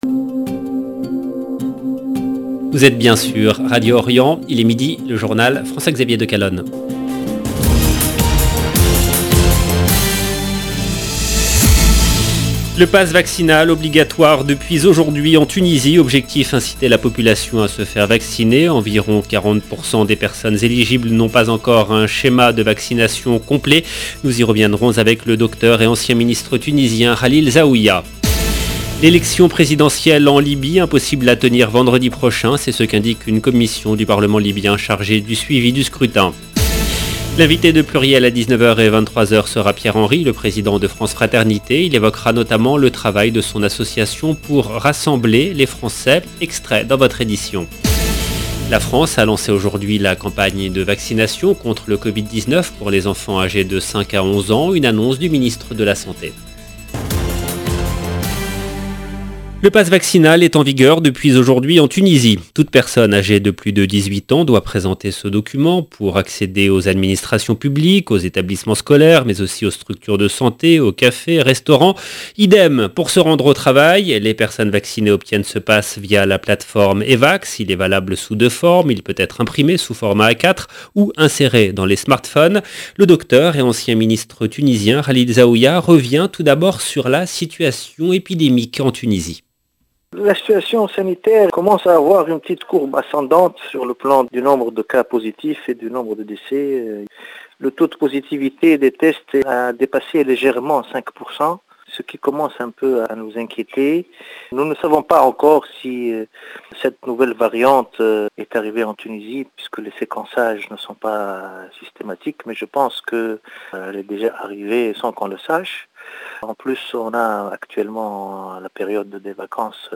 LE JOURNAL DE 12H EN LANGUE FRANCAISE DU 22/12/2021